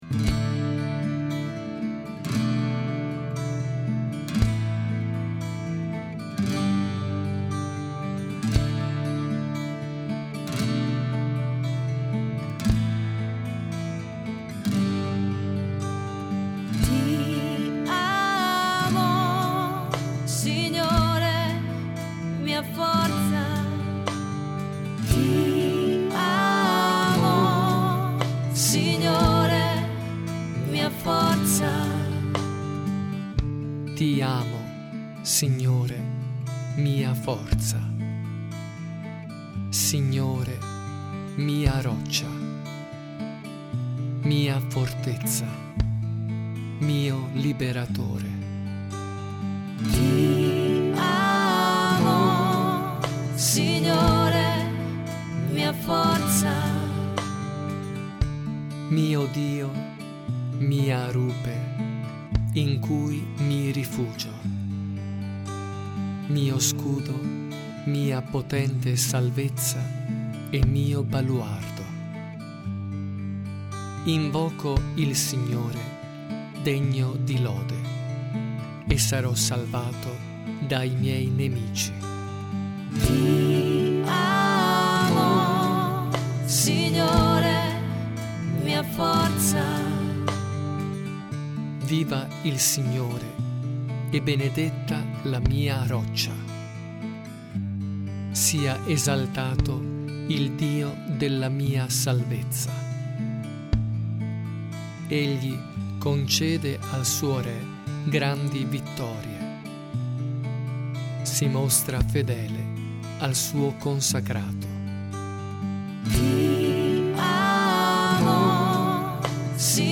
SALMO RESPONSORIALE